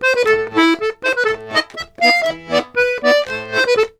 C120POLKA1-L.wav